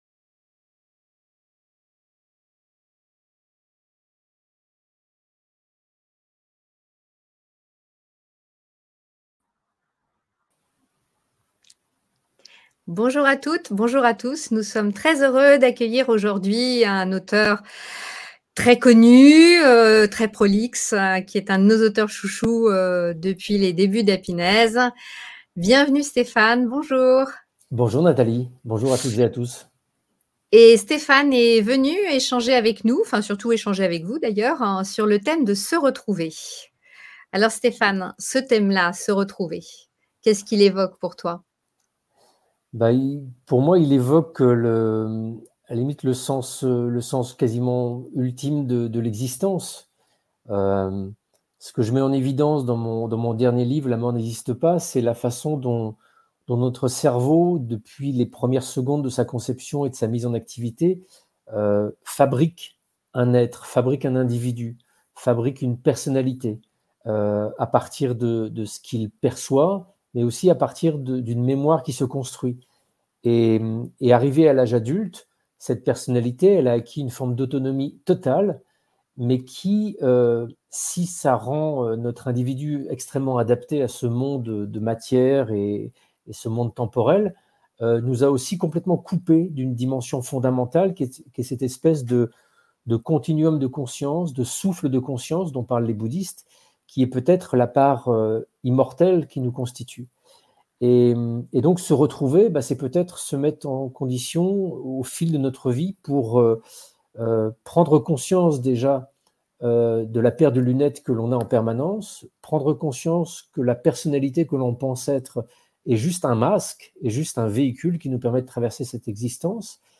Stéphane Allix - Interview 10 ans - Se retrouver - Happinez